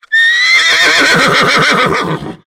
SFX_relincho2.wav